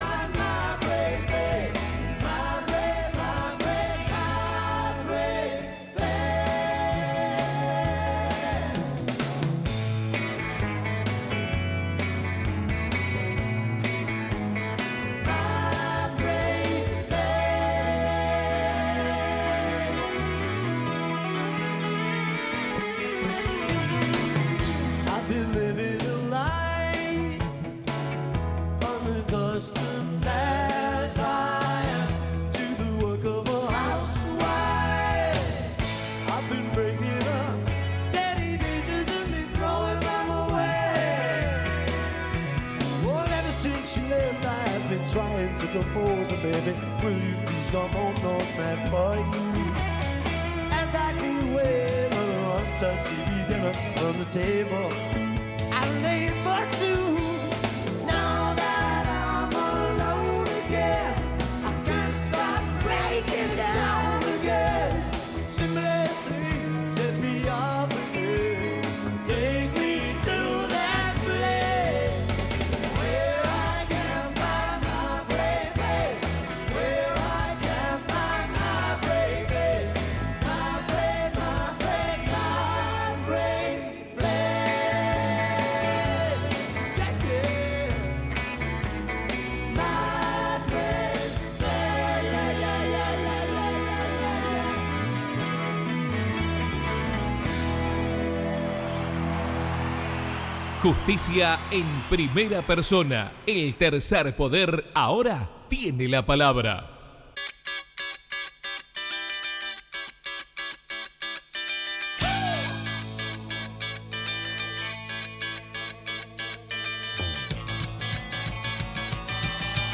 Antes de entrevistar a uno de los testigos que aportó datos claves en la investigación